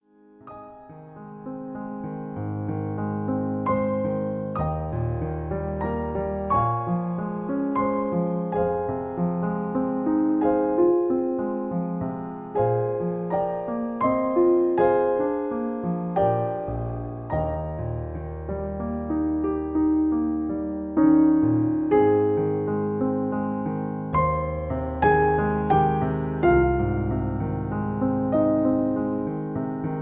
Original Key. Piano Accompaniment